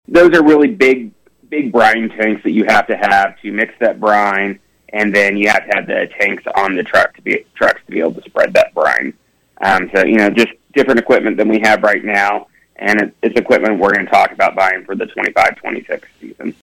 On KVOE’s Morning Show on Thursday, Cocking says he was pleased with the effects of those changes. He also says those adjustments come with the city considering other steps to improve snow removal — like pretreatment — but that’s an expensive option.